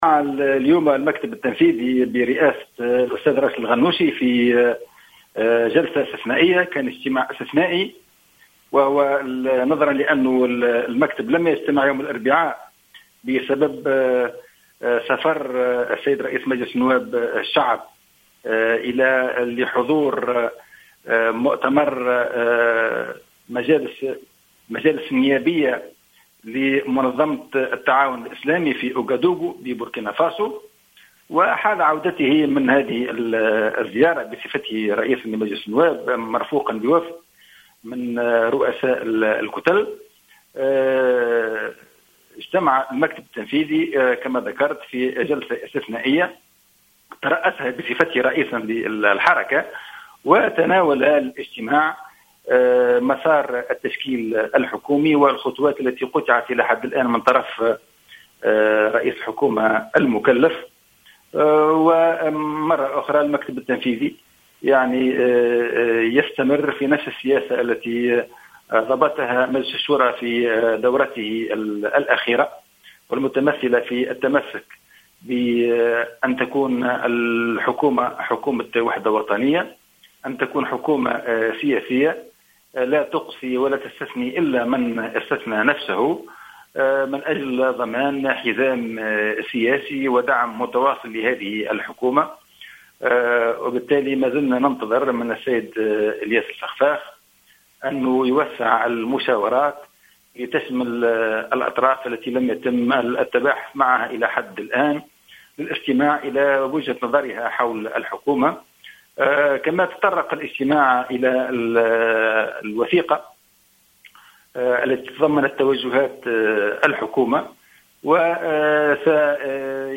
أكد القيادي بحركة النهضة العجمي الوريمي في تصريح لـ "الجوهرة أف أم" مساء اليوم السبت، تمسّك الحركة بحكومة وحدة وطنية وسياسية.